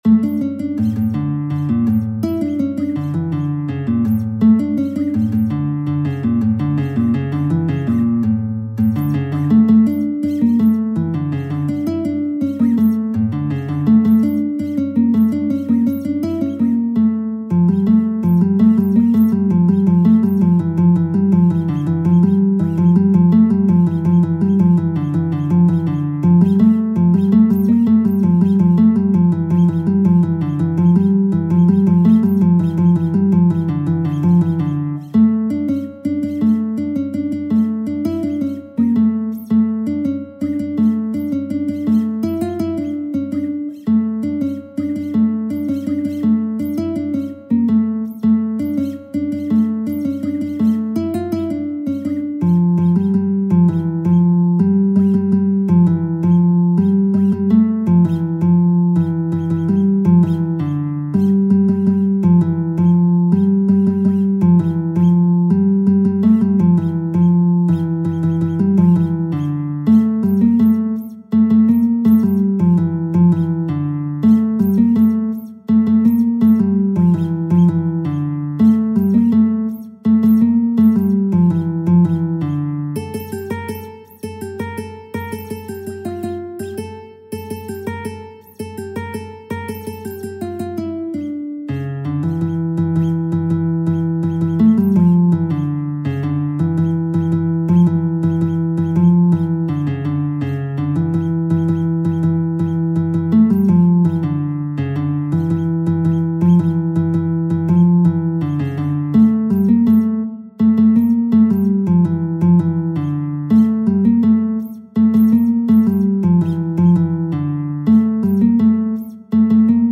تنظیم شده برای گیتار